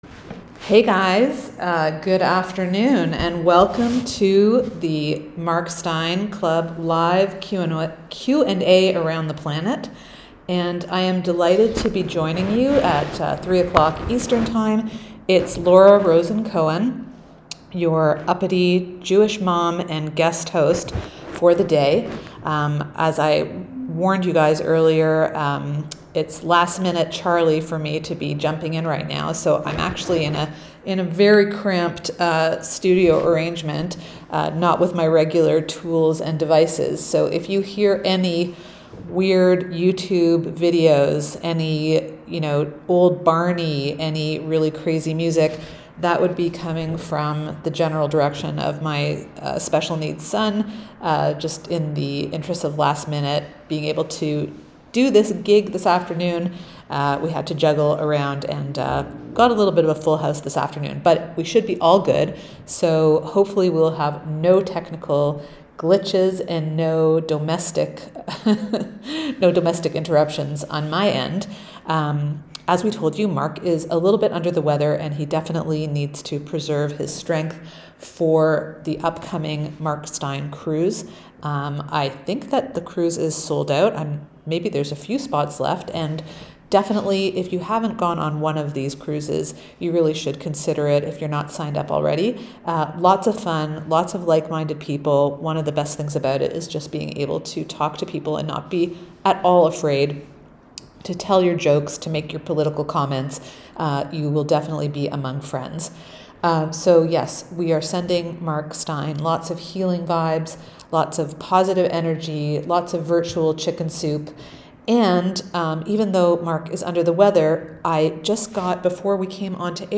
Thank you for joining us for today's Clubland Q&A live around the planet.